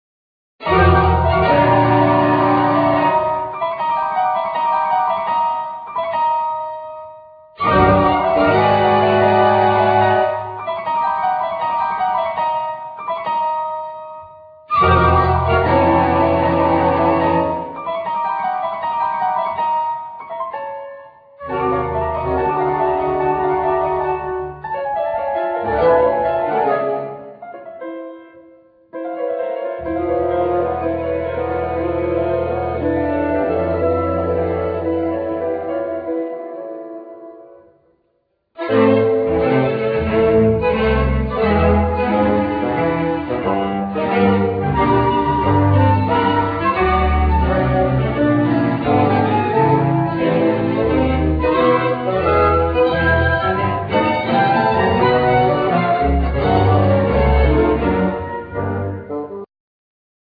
Flute
Clarinet
Basoon
Piano,Percussions
Violin,Trombone
Viola
Cello
Double bass